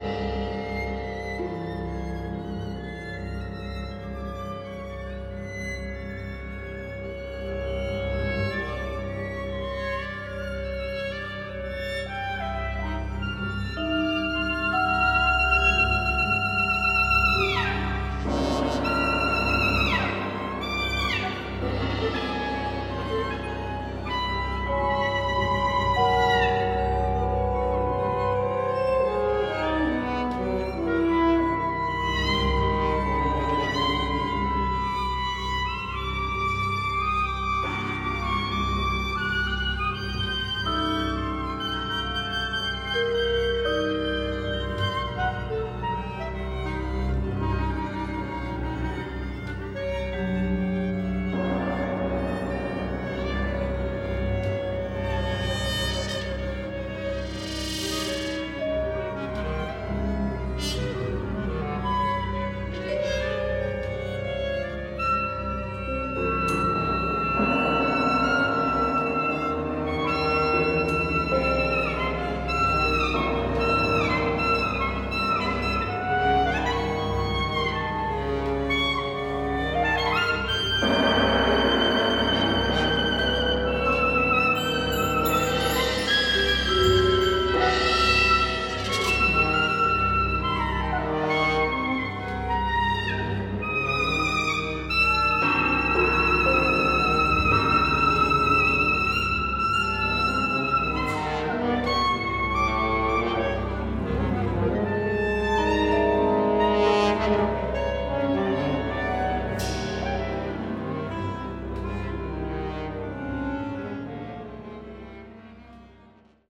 for ensemble
ensemble: cl, cor*, perc, 2vno, vla, vc, cb
French horn
September 26, 2002, Narodni dom Maribor (première)